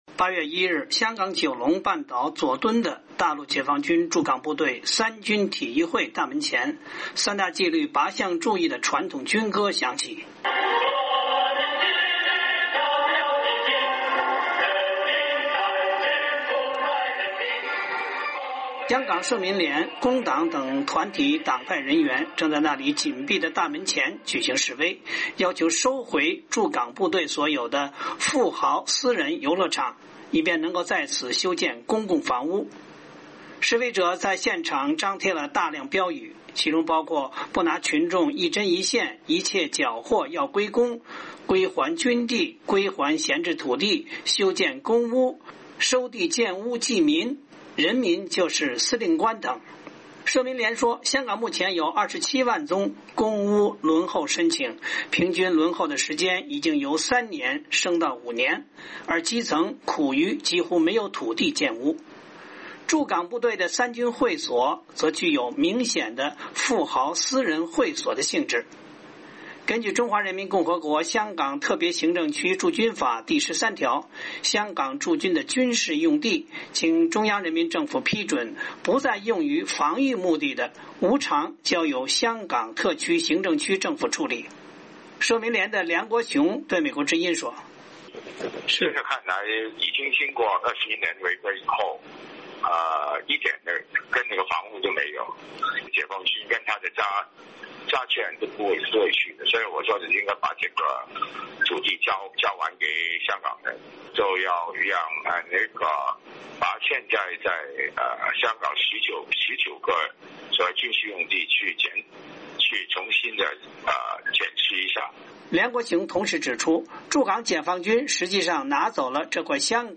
8月1日，香港九龙半岛佐敦的大陆解放军驻港部队“三军体育会”，即“三军会”大门前，《三大纪律八项注意》的传统军歌声响起。